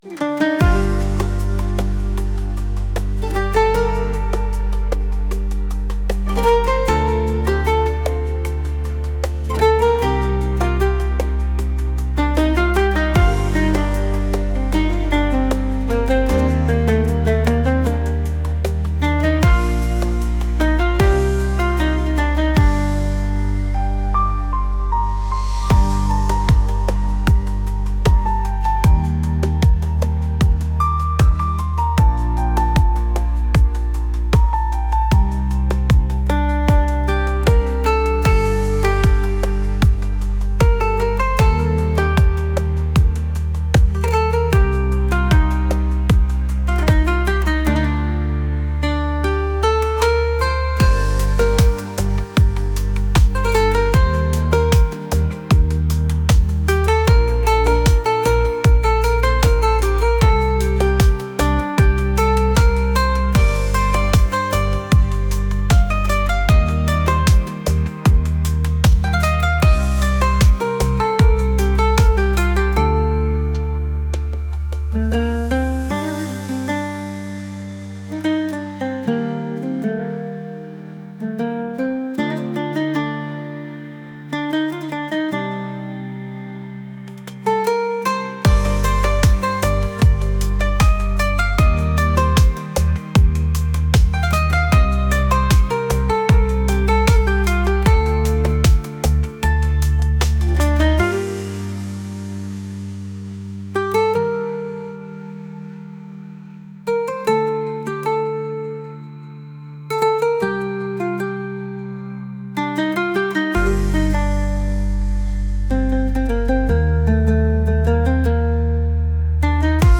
atmospheric | world